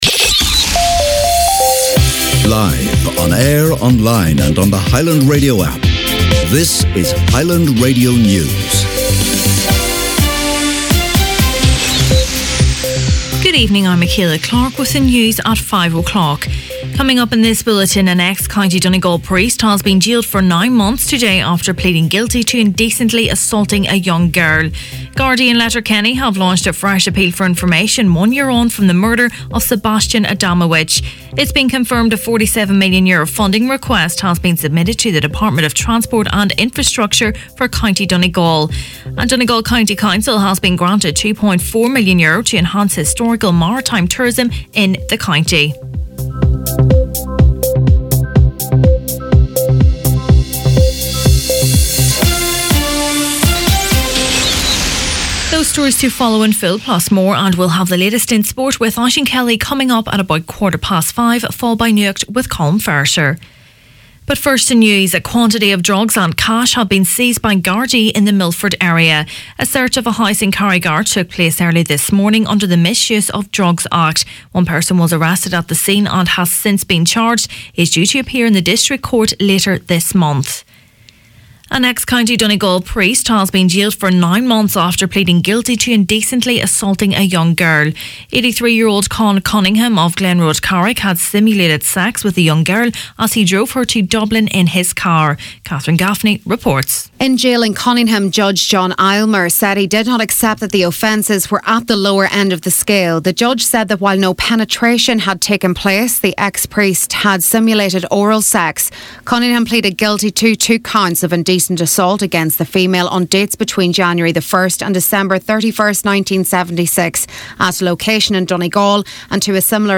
Main Evening News, Sport, Nuacht and Obituaries Friday November 2nd